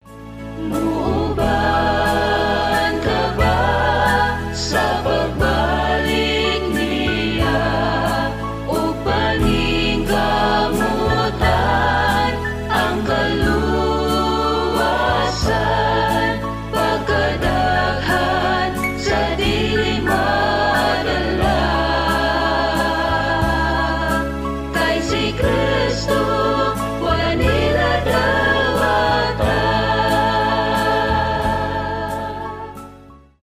христианские